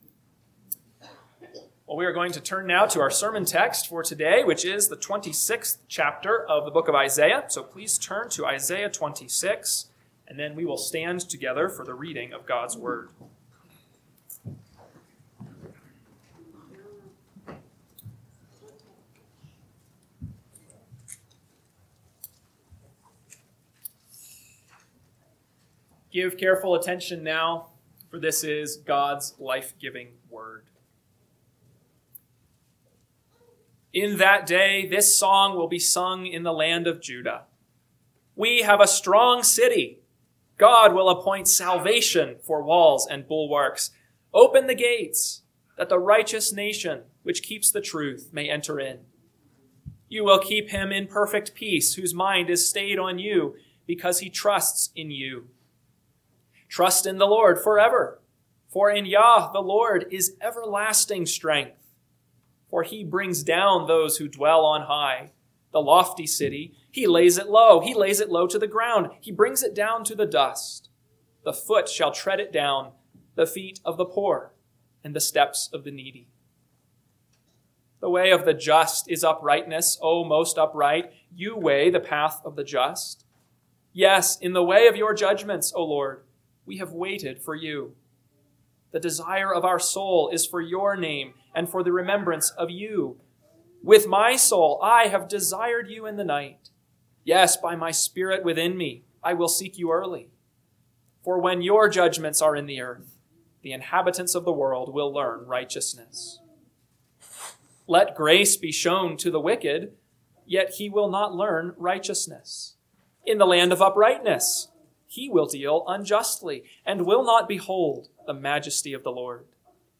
AM Sermon – 4/12/2026 – Isaiah 26 – Northwoods Sermons